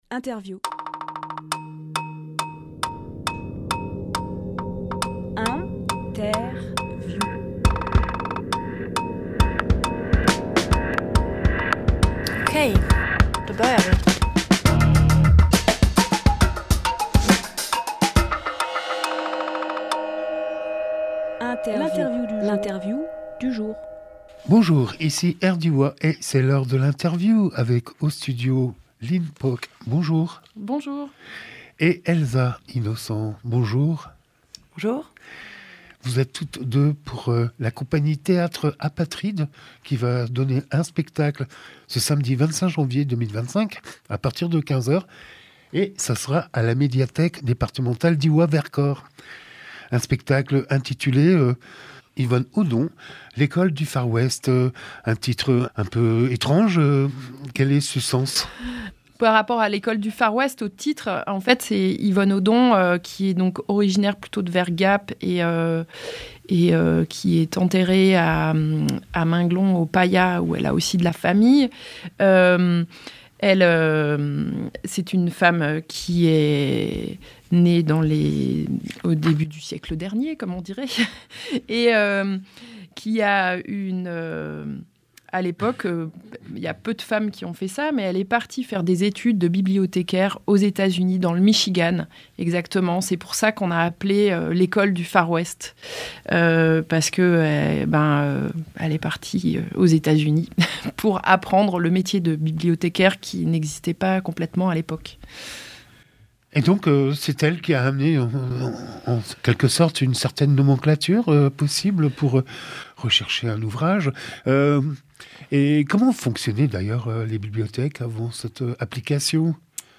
Emission - Interview Yvonne Oddon L’école du Far-West Publié le 20 janvier 2025 Partager sur…
lieu : Studio Rdwa